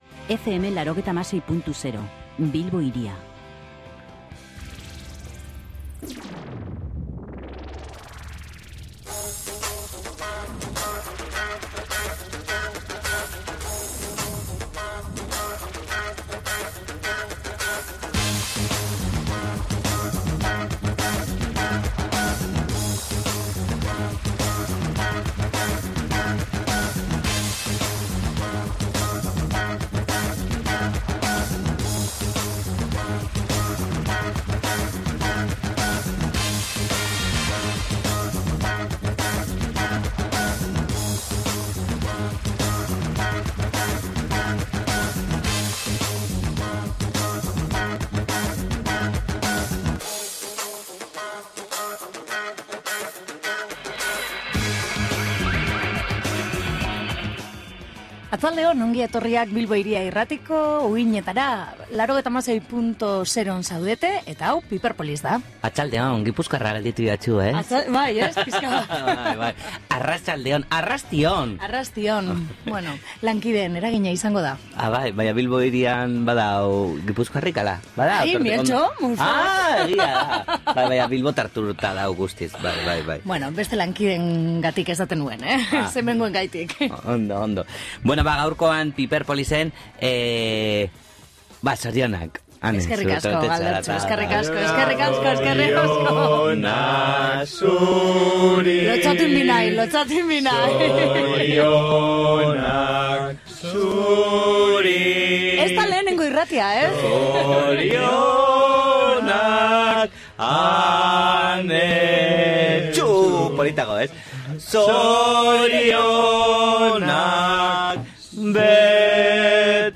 SOLASALDIA
Gaurko piperpolis saioan dantzan ibili gara Priscilla Band taldeko kideekin. Estudiora etorri dira eta lehenengo diskoan bildu dituzten kantuen istorioak kontatu dizkigute.